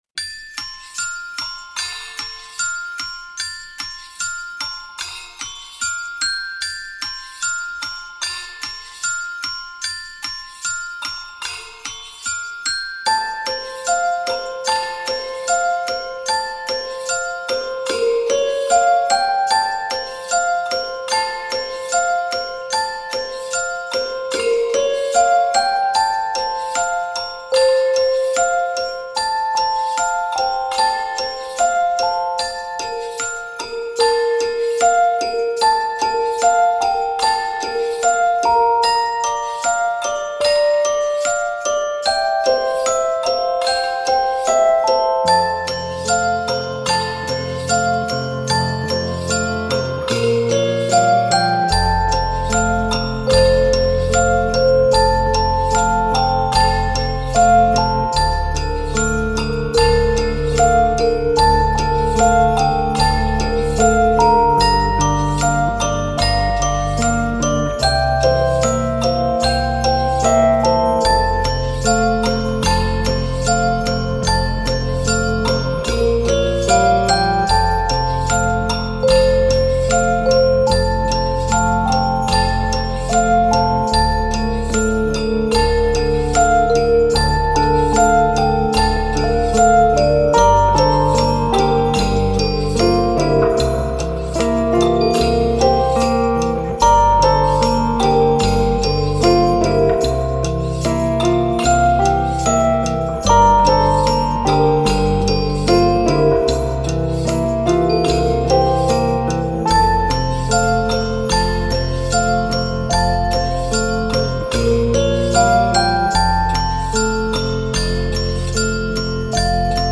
Genre: Instrumental, Music for Children